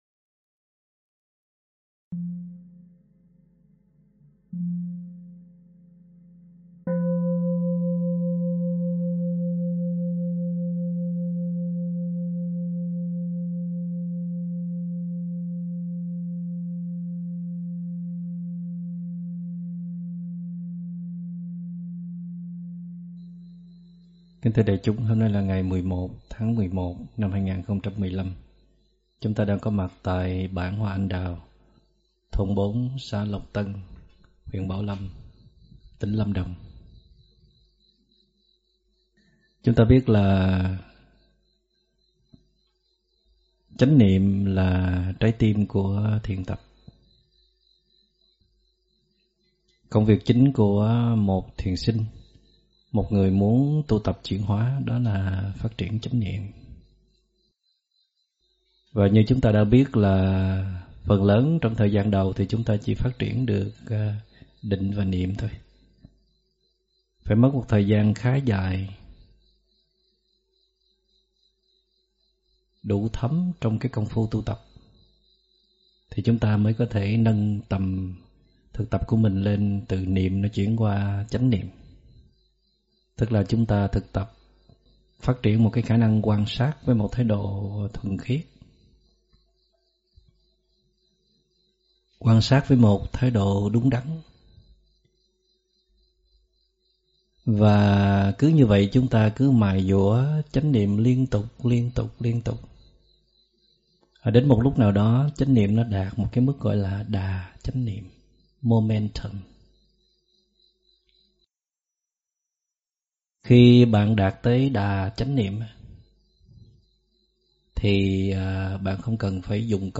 Mời quý phật tử nghe mp3 thuyết pháp Quyết Tâm Xây Dựng Đà Chánh Niệm do ĐĐ. Thích Minh Niệm giảng ngày 11 tháng 11 năm 2015